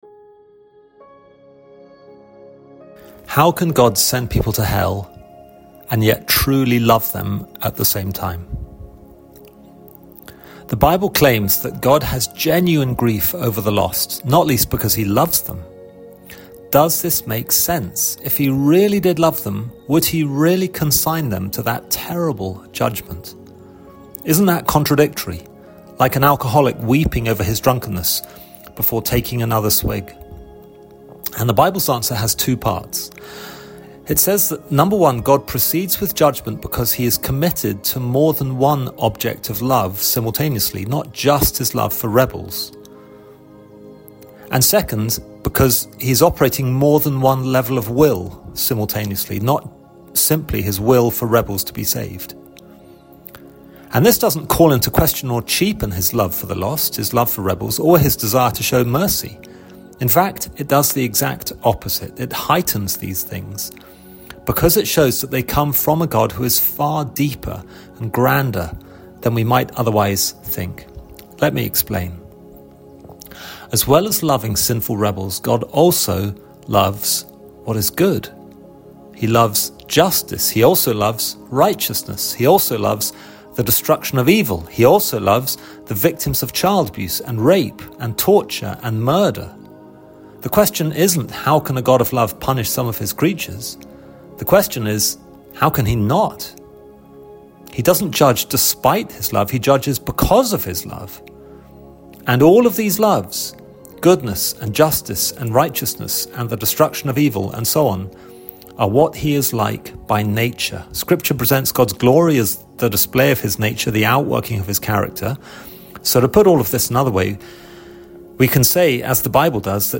Download Download Doctrinal Current Sermon How can God send people to hell and yet truly love them?